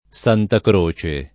Santakre] s. f. — ant. nome generico dell’«abbecedario» e specifico di una «moneta» (lucchese) — sim. il cogn. Santacroce e il top. Santa Croce (es.: S. C. sull’Arno, Tosc.; S. C. Camerina, Sic.; S. C. in Gerusalemme, a Roma; S. C. del Lago, Ven.; S. C. di Magliano, Mol.; S. C. del Sannio, Camp.)